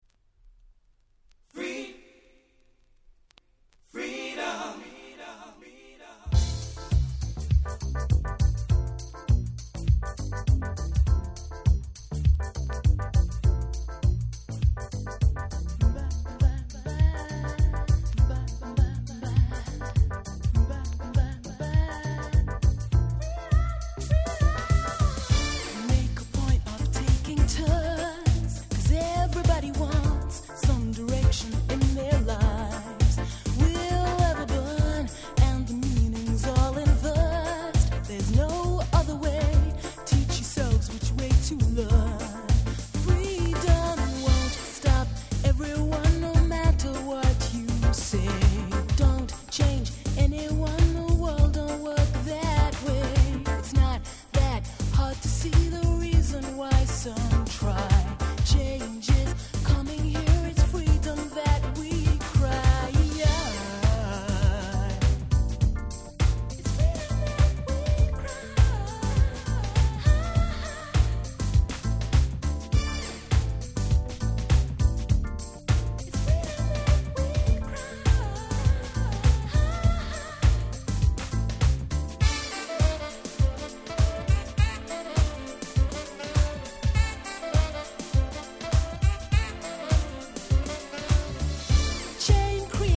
91' Nice UK Soul/R&B !!